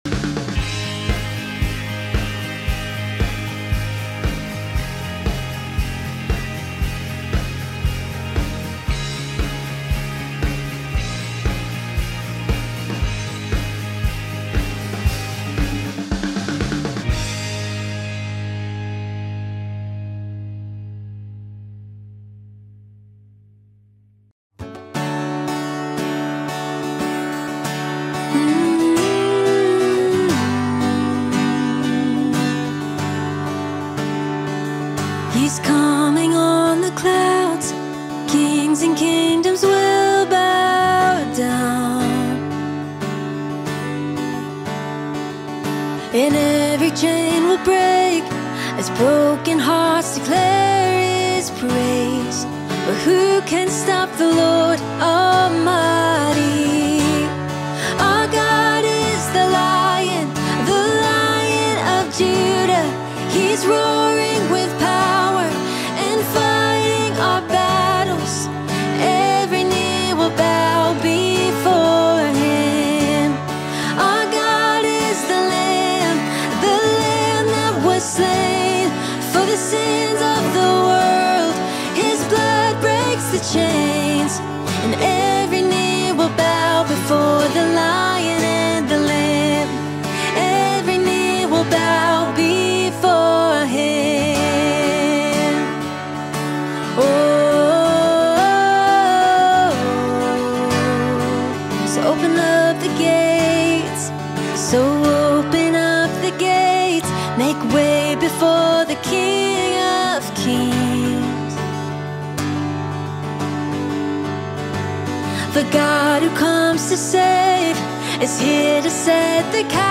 🙏Sunday Service • It All Comes Down To This! 🙏